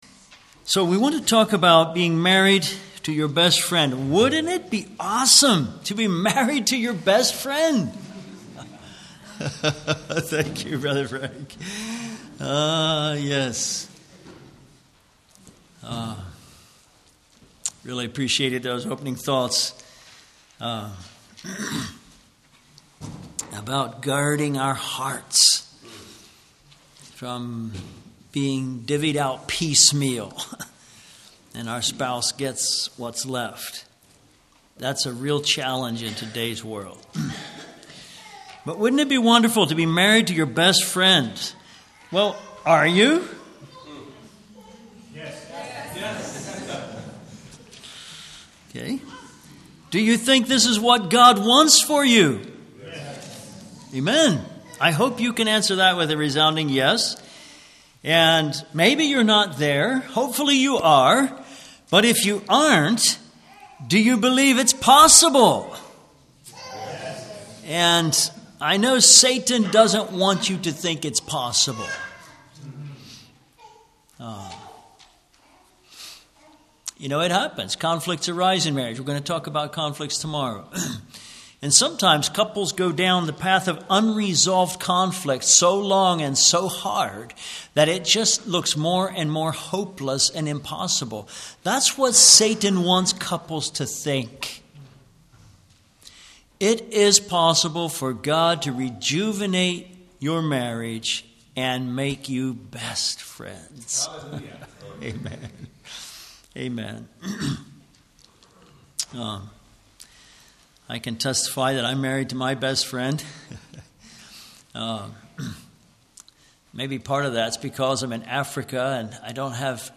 Service Type: Ladies Seminar, Men's Seminar